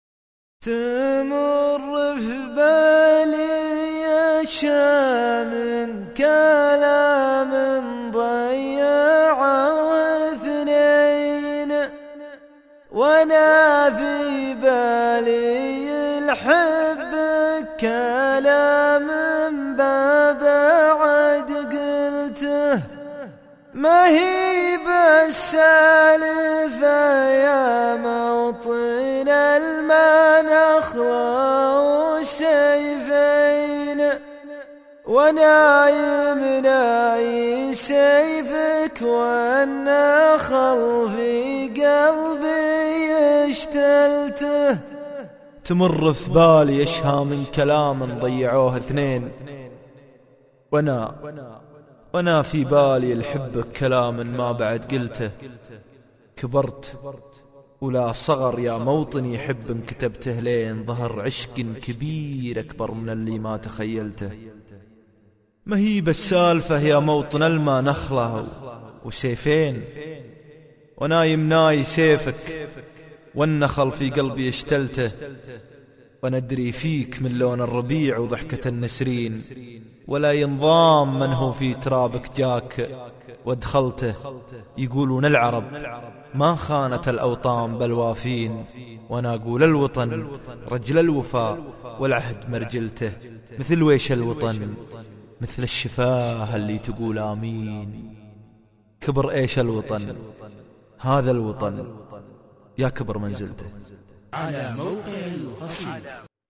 شيله+ القاء